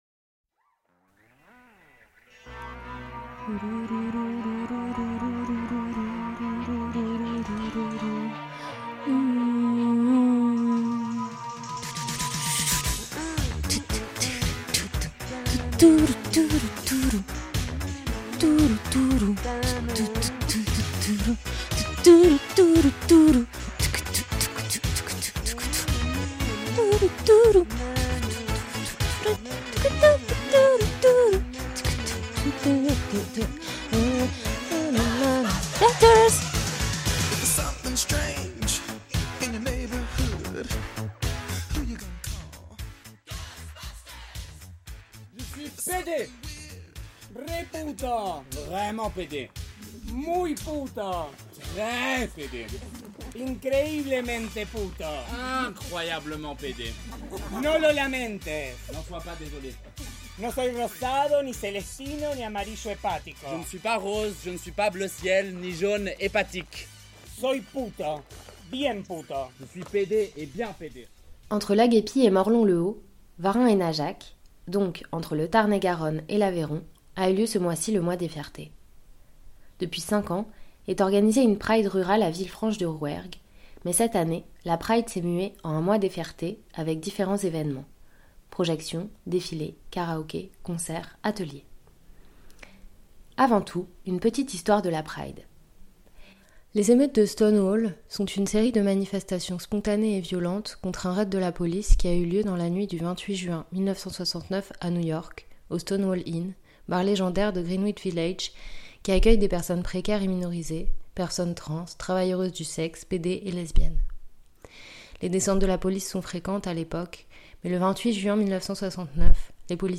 les participant.e.s de la Pride de Najac